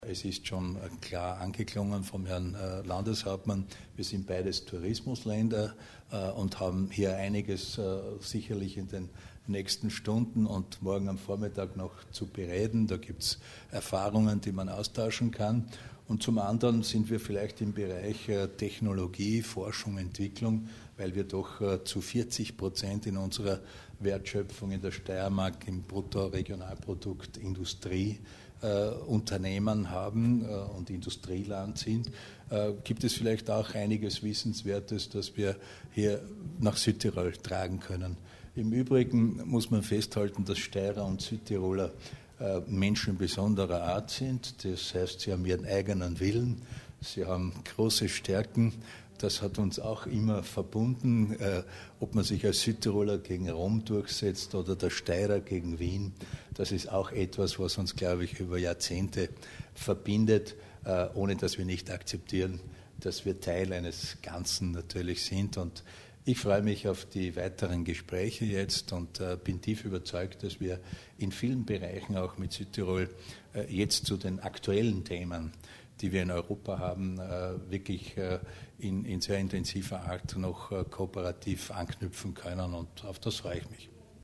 Landeshauptmann Voves über das Treffen